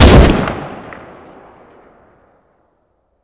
garand_short.wav